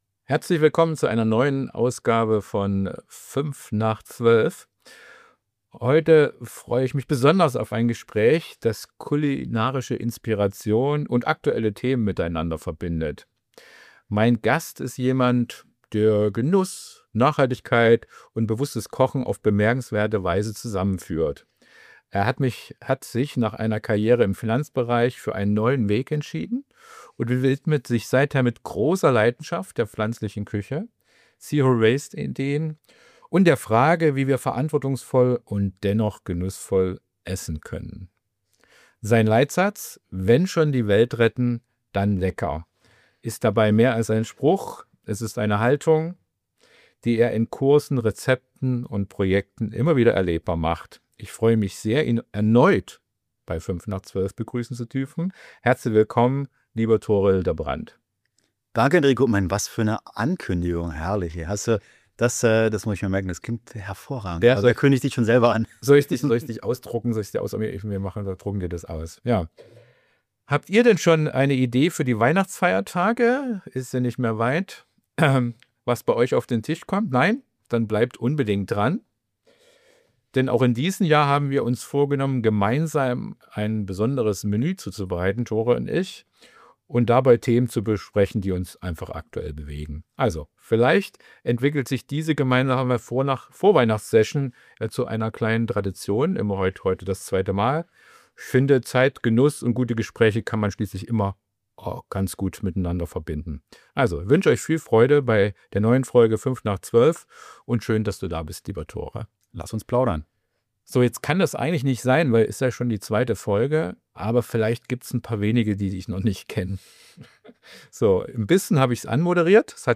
Während es in der Küche duftet und brutzelt, sprechen wir darüber, wie man Lebensmittelverschwendung reduziert, welche einfachen Gewohnheiten den Alltag nachhaltiger machen und wie man entspannt durch die Feiertage kommt.